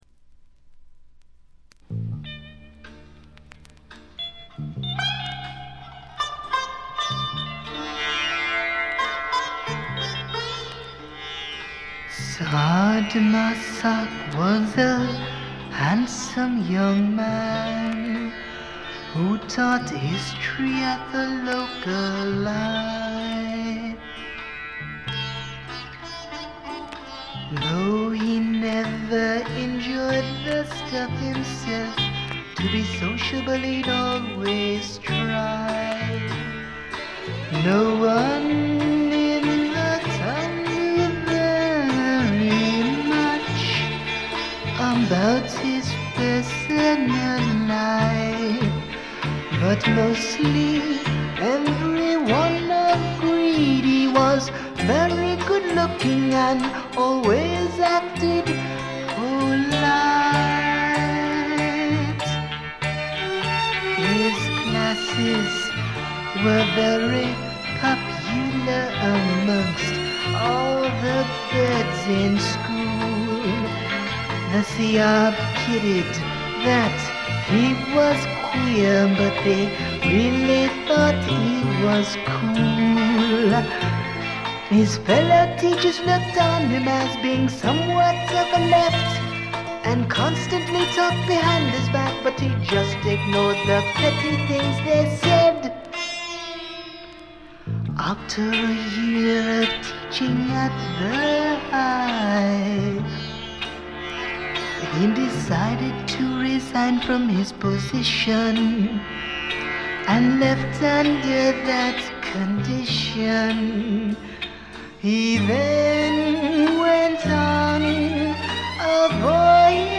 黒人臭さのまったくないヴォイスがまた素晴らしいです。
試聴曲は現品からの取り込み音源です。